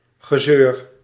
Ääntäminen
IPA: /ɣə.ˈzøːr/